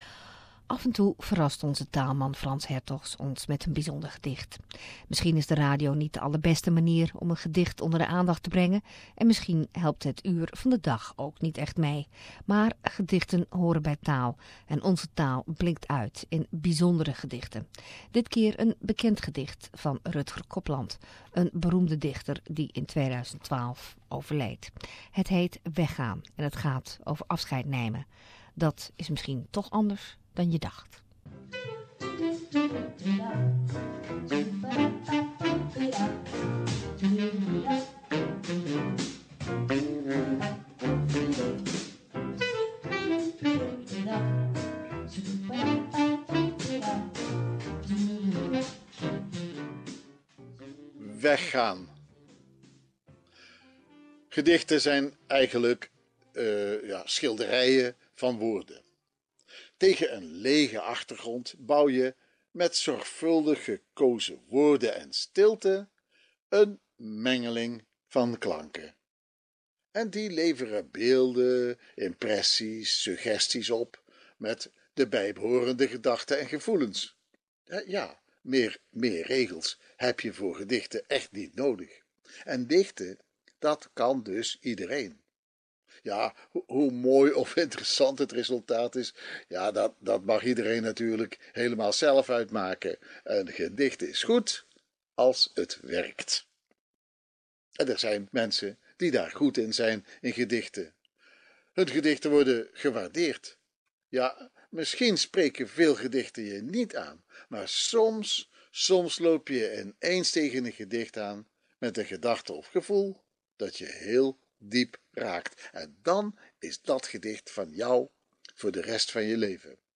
reads a poem